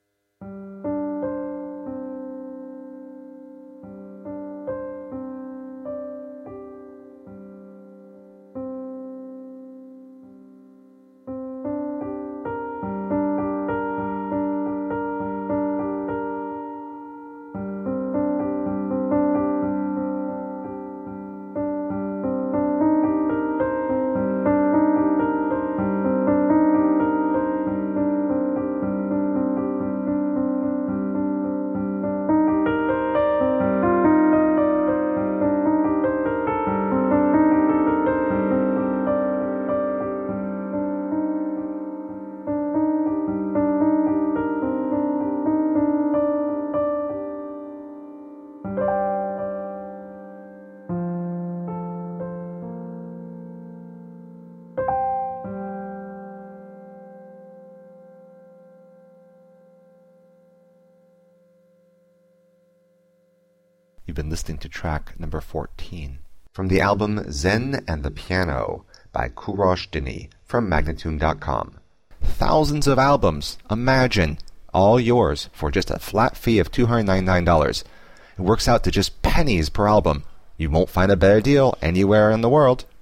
Minimalist piano with slashes of ambient electronics.
a relaxing set of solo piano works